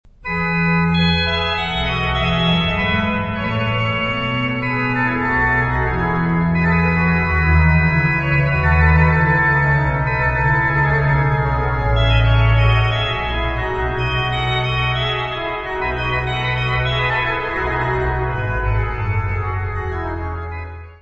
Organ works
Notas:  Este disco foi gravado ao vivo na Basilica de St. Alexander und Theodor, Benediktinerabtei em Ottobeuren na Alemanha, durante o mês de Maio de 1998; O orgão utilizado na gravação foi construído por Johann Andreas Silbermann, entre 1756-1761; No do Serviço de Aquisições e Tratamento Técnico